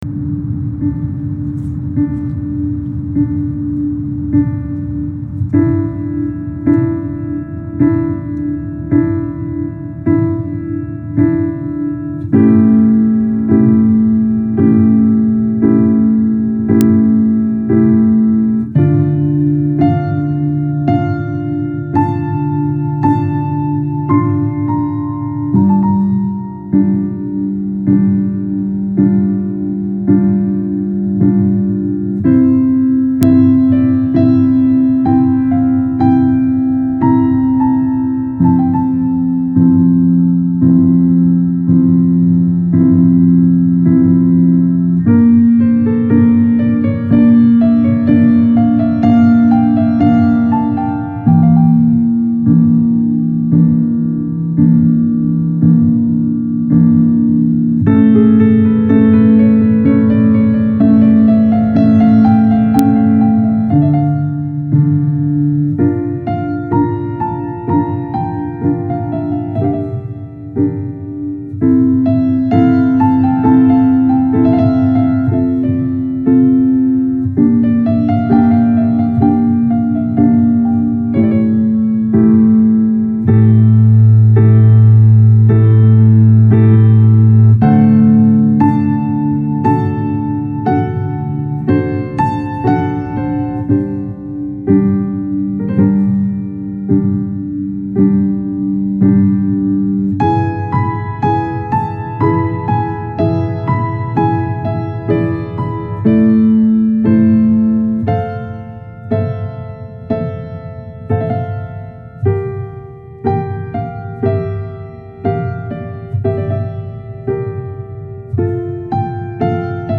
سبک تیتراژ , تیتراژ فیلم